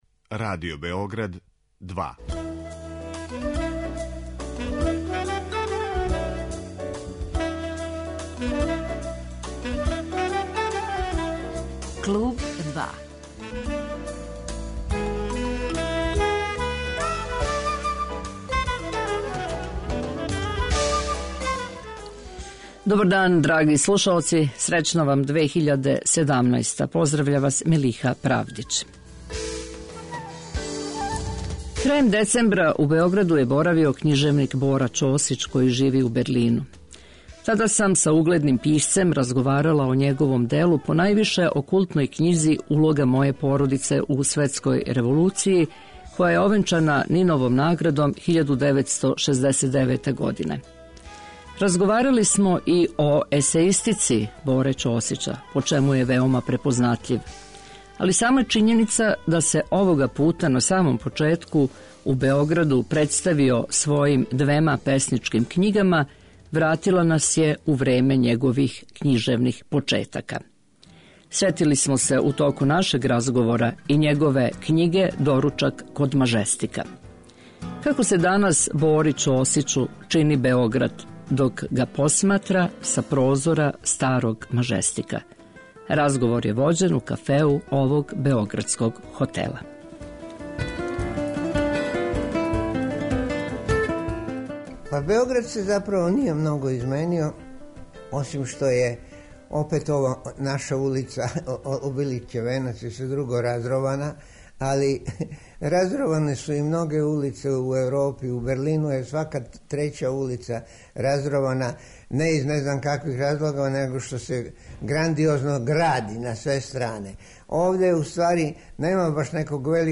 Гост 'Клуба 2' је књижевник Бора Ћосић
Како се данас Бори Ћосићу чини Београд, док га посматра са прозора старог Мажестика? Разговор је вођен у кафеу овог београдског хотела.